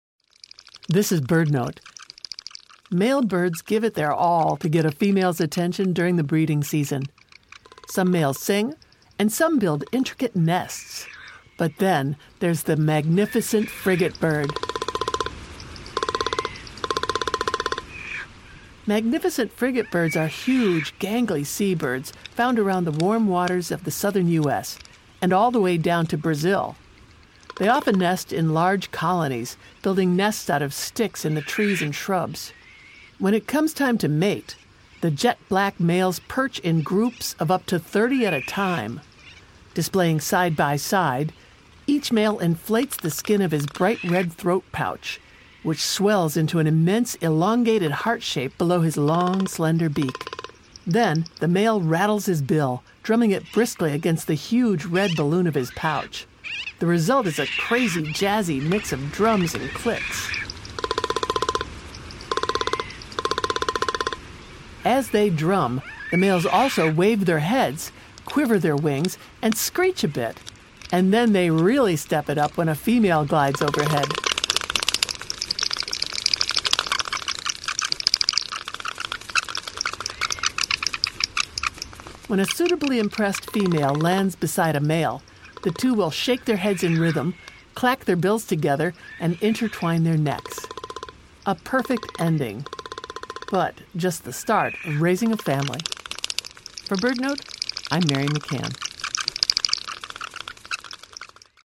Magnificent Frigatebirds are huge, gangly seabirds found around the warm waters of the Western Hemisphere. When it comes time to mate, males inflate giant red throat sacs, then rattle and drum their bills against them to create jazzy percussive sounds..